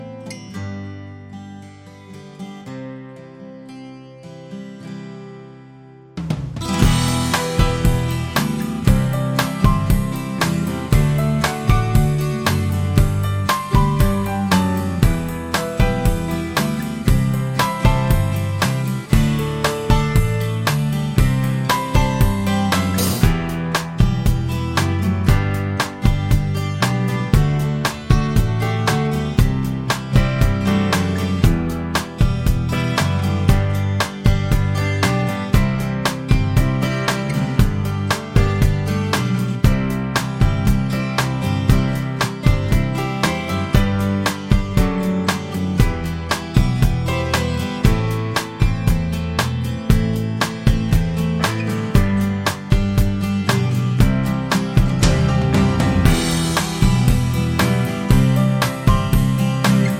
no harmonica Rock 4:53 Buy £1.50